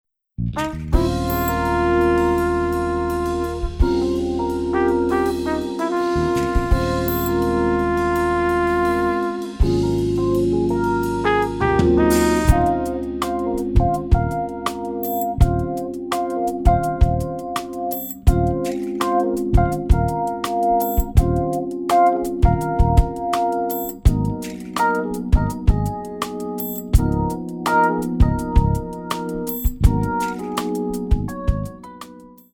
Lyrical Jazz / R&B
4 bar intro
moderato